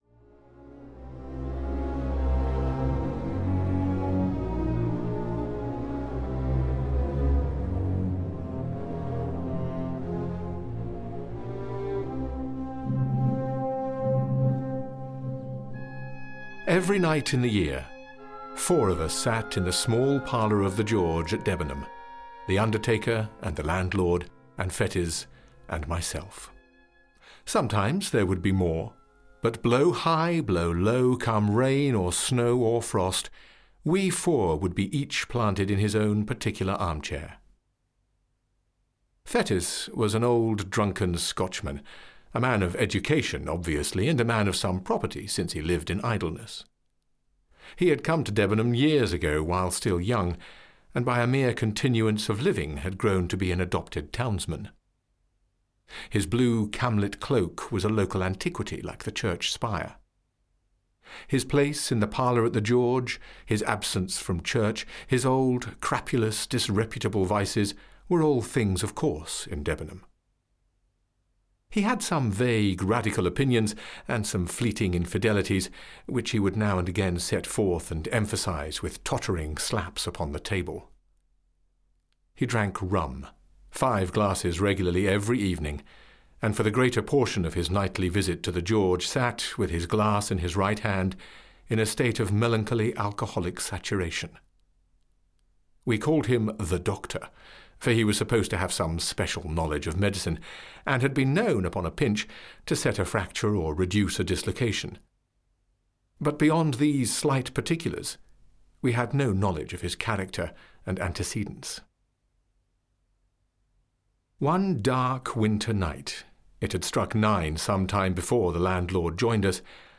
The Body Snatcher and Other Stories (EN) audiokniha
Ukázka z knihy